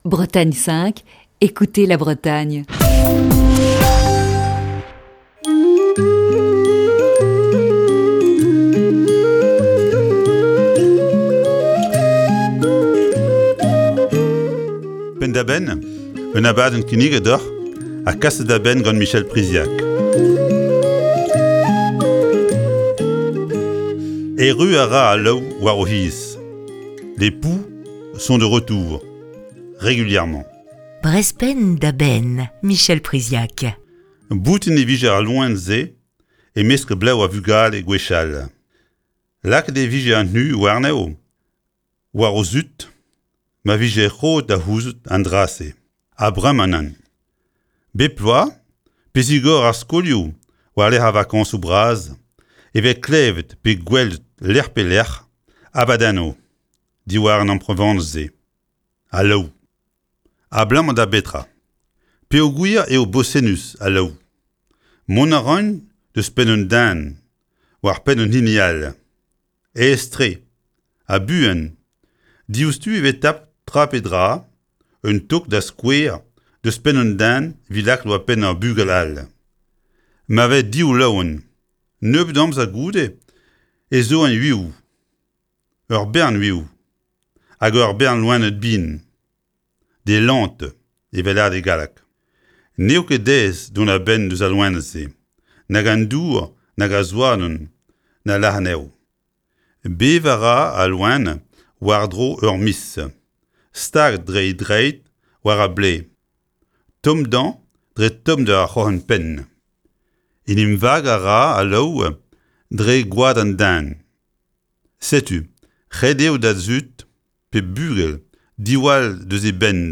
Chronique du 14 juillet 2020.